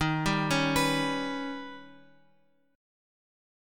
D#+7 chord {x 6 9 6 8 7} chord